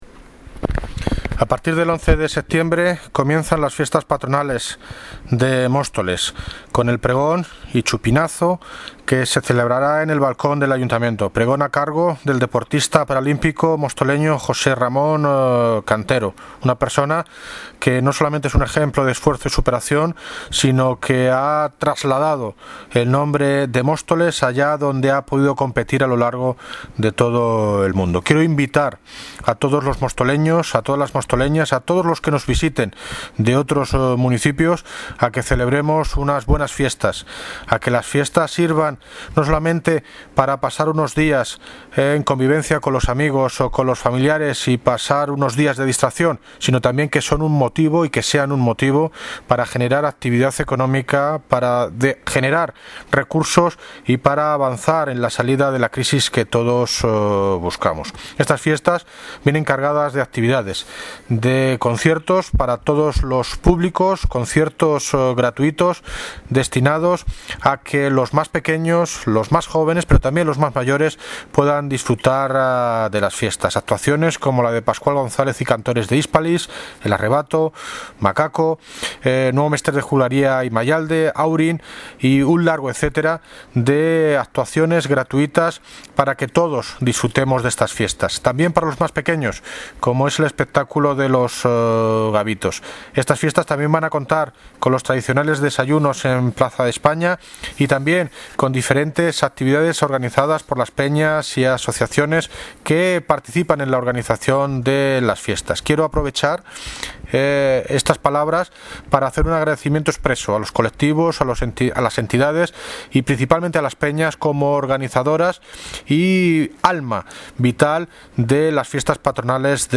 Audio - David Lucas (Alcalde de Móstoles) Sobre programacion fiestas patronales 2015